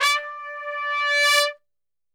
D 3 TRPSWL.wav